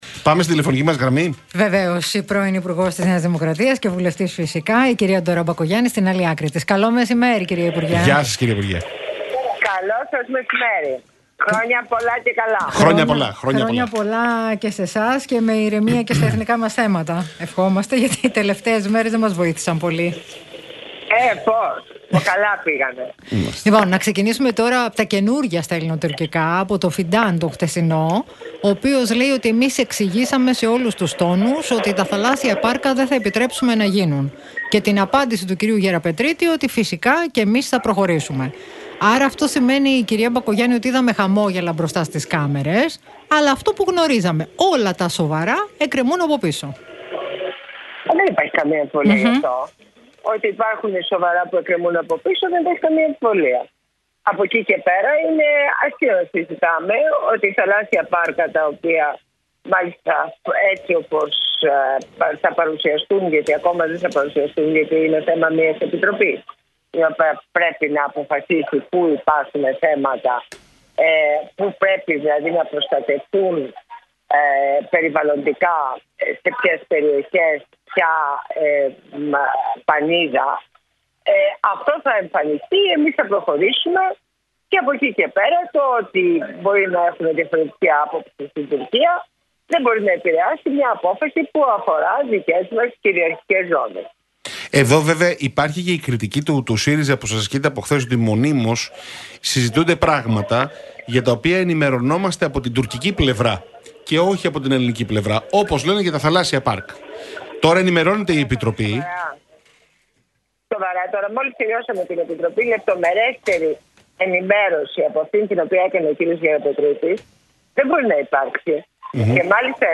Μπακογιάννη στον Realfm 97,8: Για τα εθνικά θέματα μιλάμε με τους σοβαρούς ανθρώπους του ΣΥΡΙΖΑ